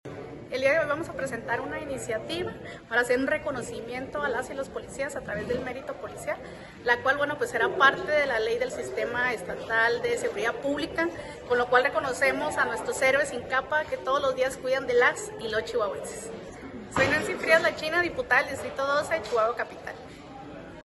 AUDIO: NANCY FRÍAS, INTEGRANTES DEL GRUPO PARLAMENTARIO DEL PARTIDO ACCIÓN NACIONAL (PAN), EN EL H. CONGRESO DEL ESTADO DE CHIHUAHUA